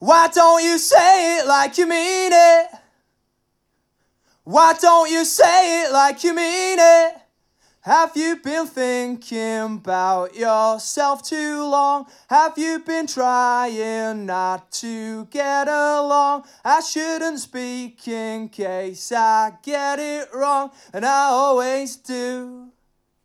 Próbki dzwiękowe Audio Technica AE-3000
Audio Technica AE3000 mikrofon - męski wokal